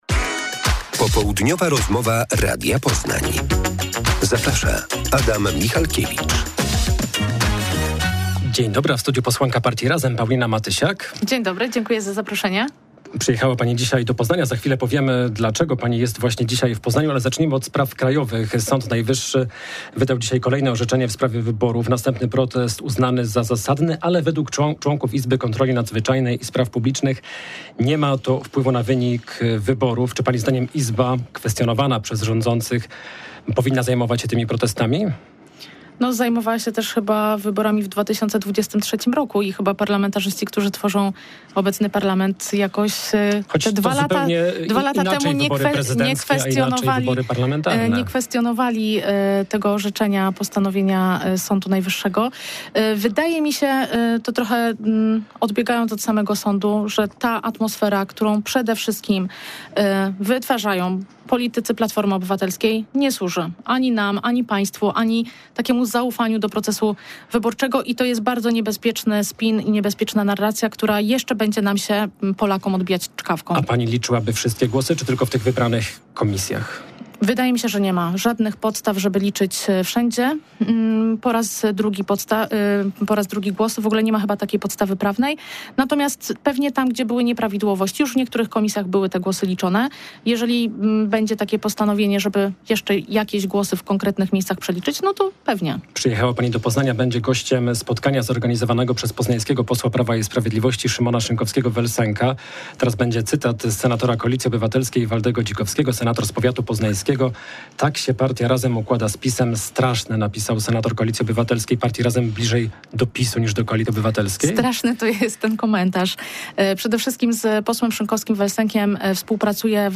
Popołudniowa rozmowa Radia Poznań – Paulina Matysiak